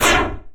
Retro Swooosh 02.wav